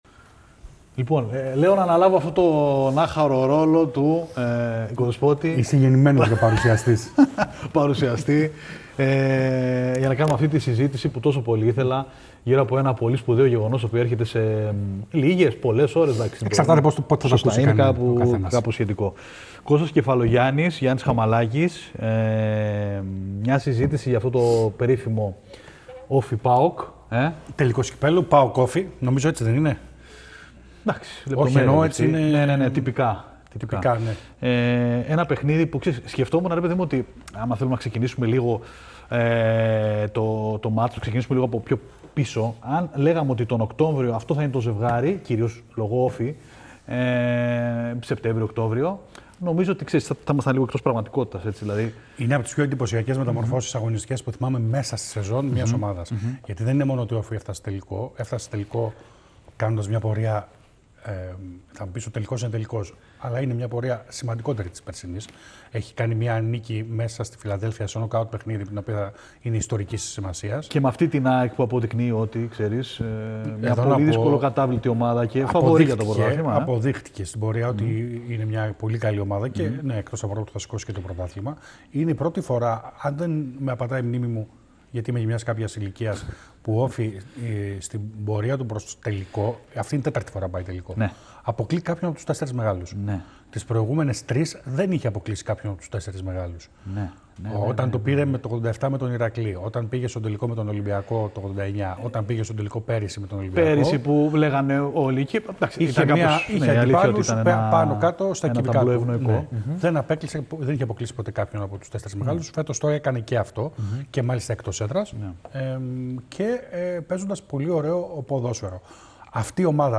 Η διαδρομή των δύο διεκδικητών του Κυπέλλου, τα σημεία ”κλειδιά” του αγώνα, οι πρωταγωνιστές, η απουσία Γιακουμάκη, οι πιθανές ενδεκάδες, κ.α σε μια ποδοσφαιρική συζήτηση με μπόλικο ενδιαφέρον.